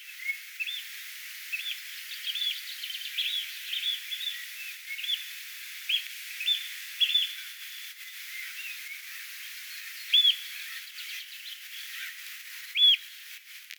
rantaharakka saapuu rannalle ruokailemaan
Rantaharakkojen ja kuovien ääniä kuului paljon tänään.
rantaharakka_saapuu_pienelle_kahlaajarannalle.mp3